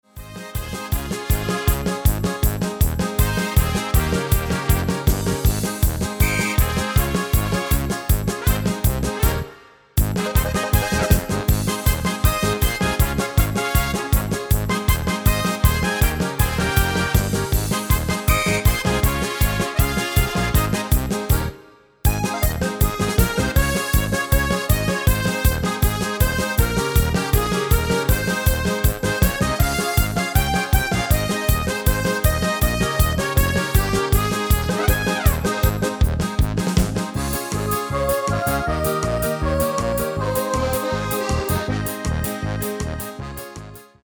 Demo/Koop midifile
Genre: Carnaval / Party / Apres Ski
Toonsoort: Ab/A
- Vocal harmony tracks